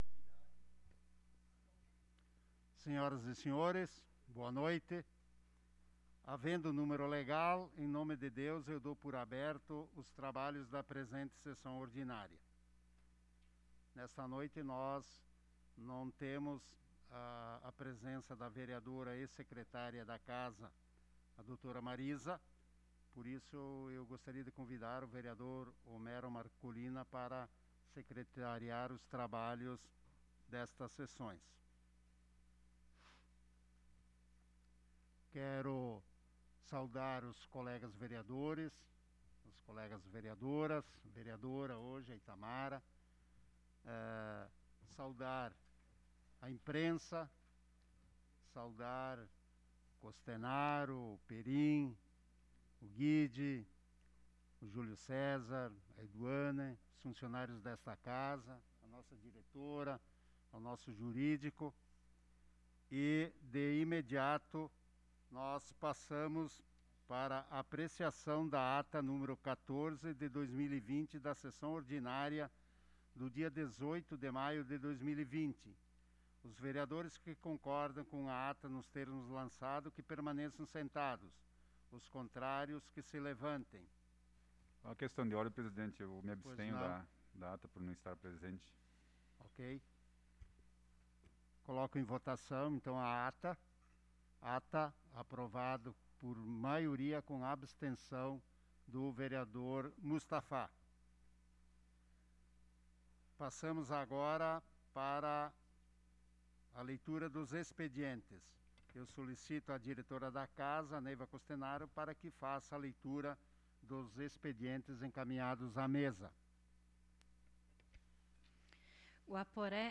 Sessão Ordinária do dia 25 de Maio de 2020 - Sessão 15